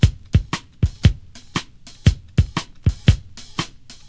• 118 Bpm Drum Groove E Key.wav
Free drum loop sample - kick tuned to the E note. Loudest frequency: 575Hz